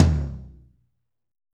Index of /90_sSampleCDs/Northstar - Drumscapes Roland/DRM_Medium Rock/TOM_M_R Toms x
TOM M R L00L.wav